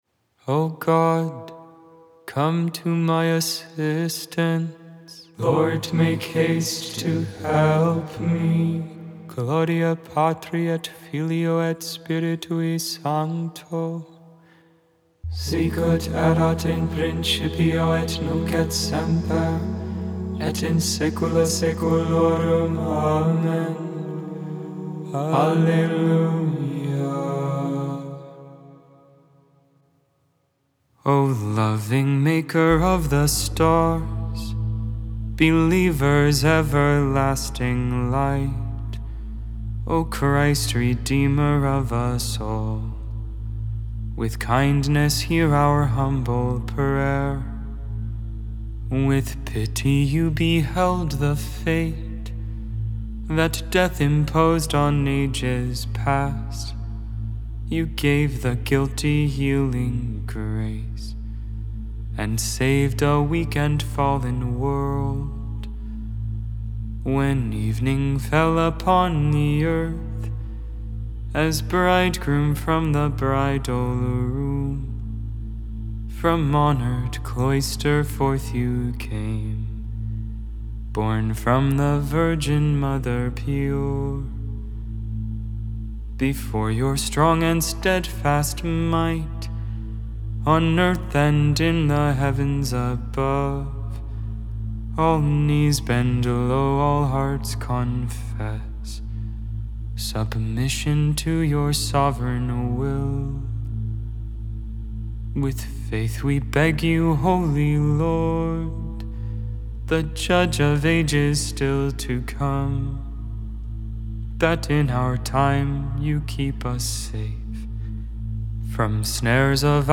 Gregorian tone 4E
Advent tone, StH adaptation
Gregorian tone 2, simple
Monastic tone, English